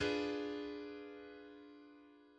Do7 D verminderd septiem d f a♭ c♭